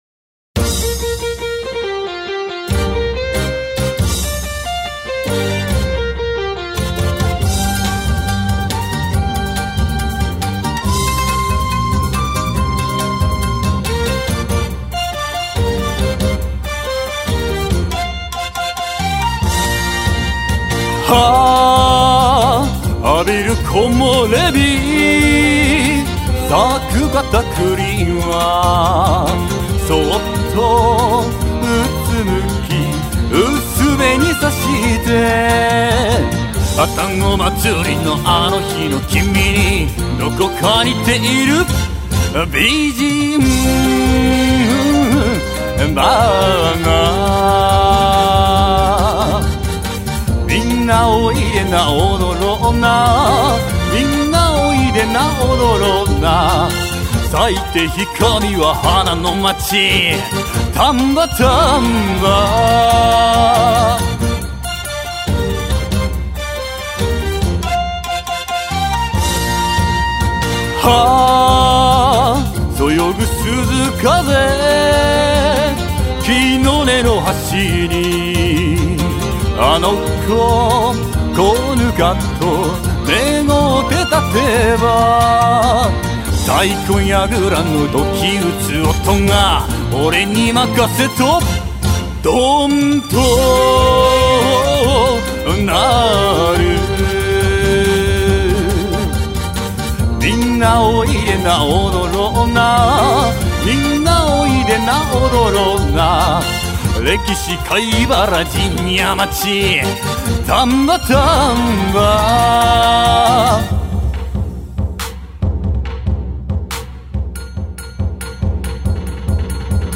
掛声無